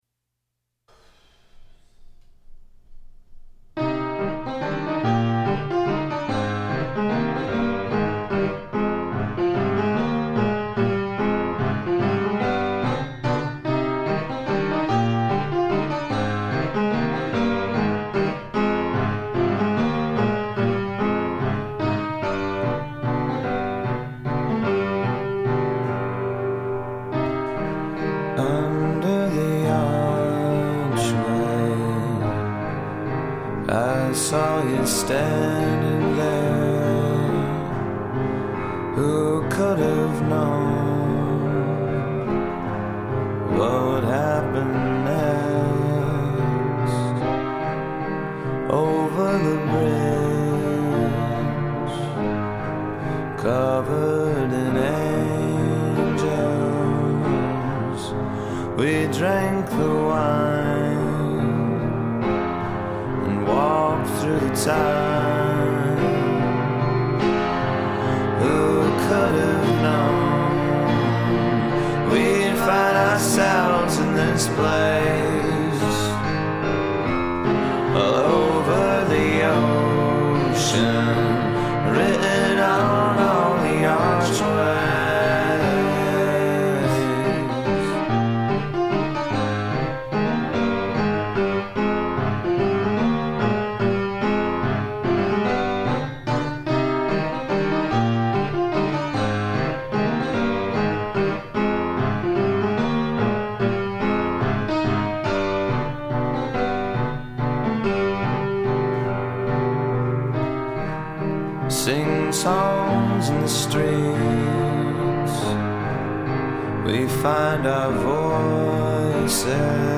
piano songs